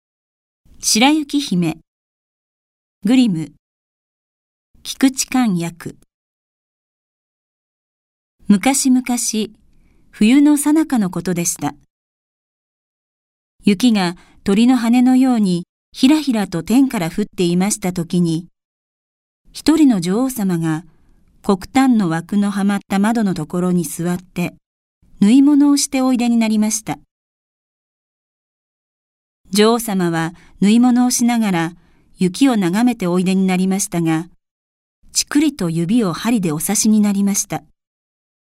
• 弊社録音スタジオ
朗読ＣＤ　朗読街道139「白雪姫・ヘンゼルとグレーテル」グリム兄弟
朗読街道は作品の価値を損なうことなくノーカットで朗読しています。